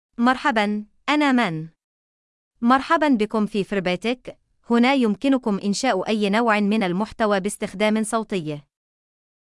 MounaFemale Arabic AI voice
Mouna is a female AI voice for Arabic (Morocco).
Voice sample
Female
Mouna delivers clear pronunciation with authentic Morocco Arabic intonation, making your content sound professionally produced.